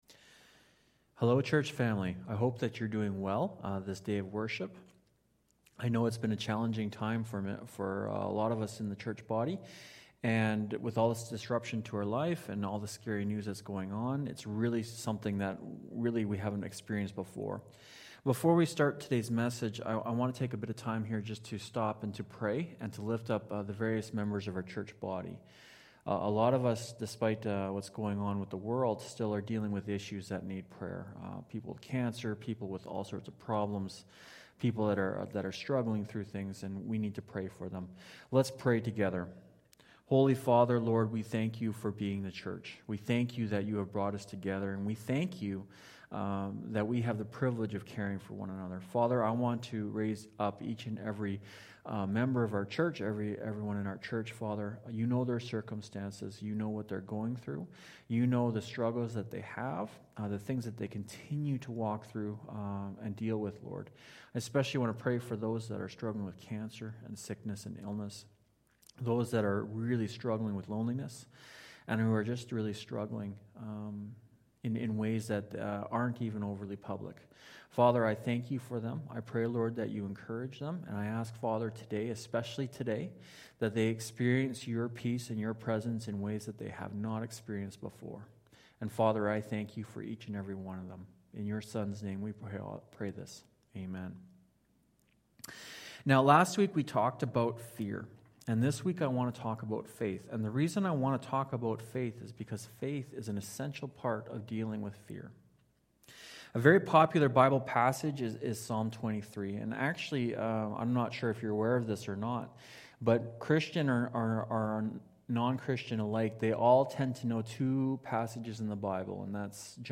Service Type: Sermon only